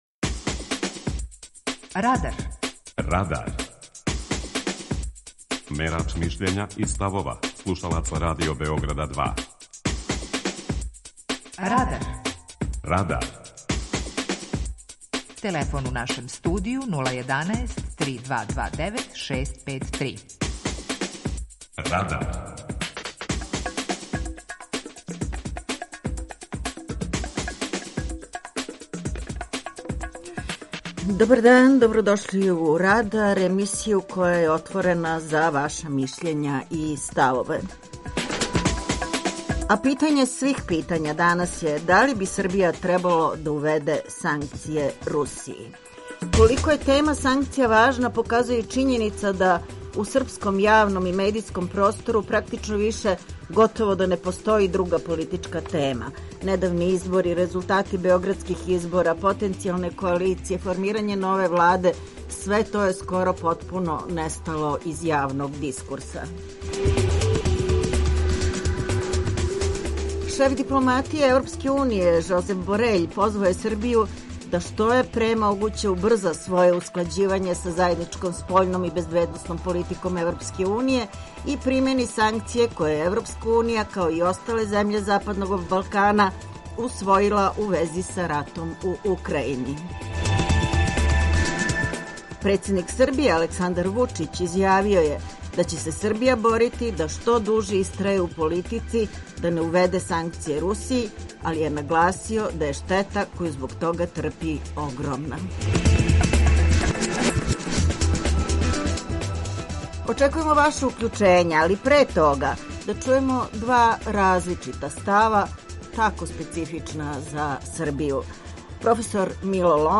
Питање Радара данас гласи: Да ли би требало увести санкције Русији? преузми : 18.44 MB Радар Autor: Група аутора У емисији „Радар", гости и слушаоци разговарају о актуелним темама из друштвеног и културног живота.